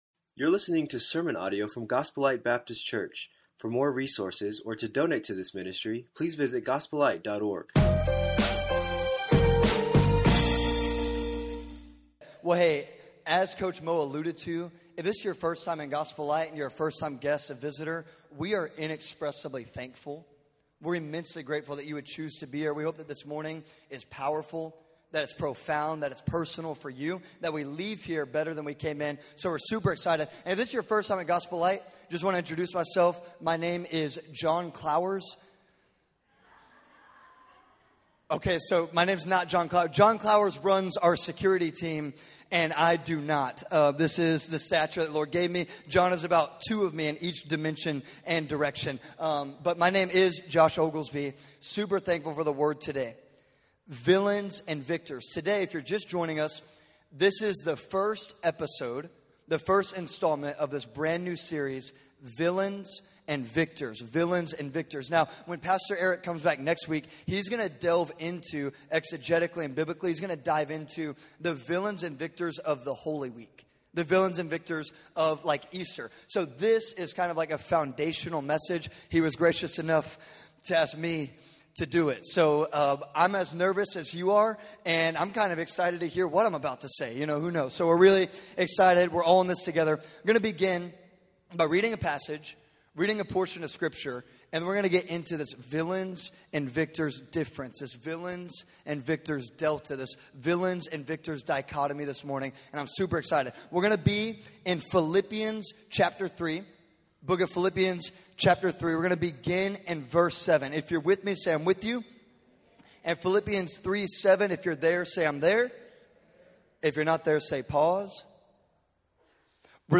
Villains and Victors - Sermon 1